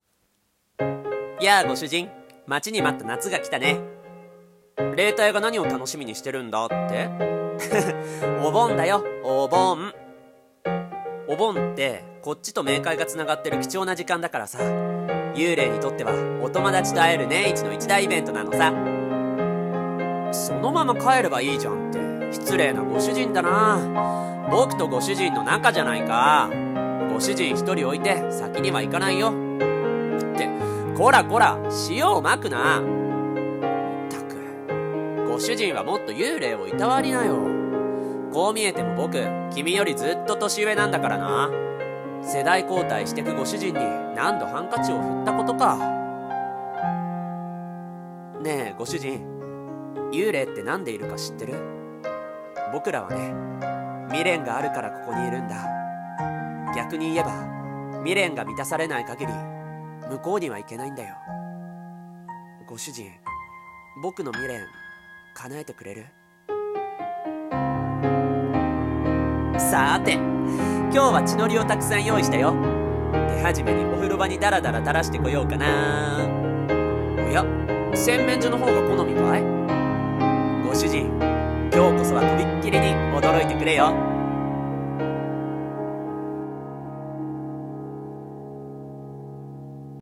【台本】幽霊さんは帰らない【コメディ×ホラー】 声劇